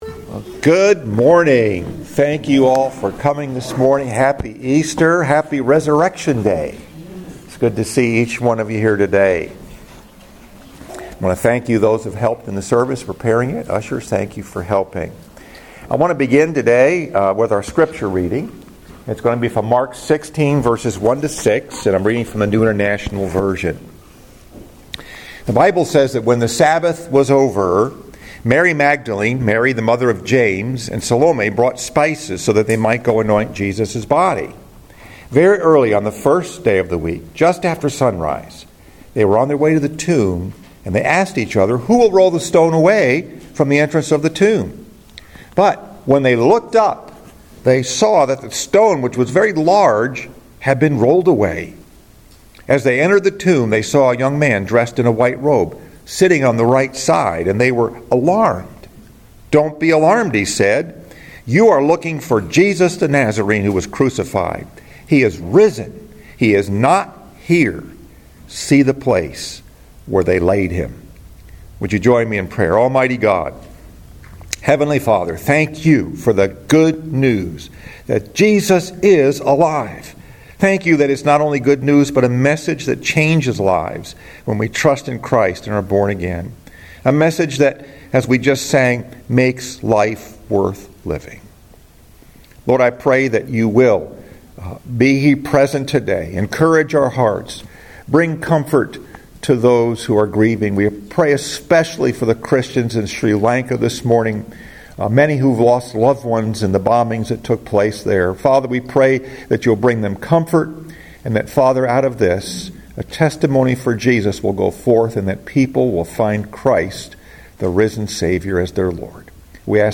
Scripture: Mark 16:1-6 Easter Sunday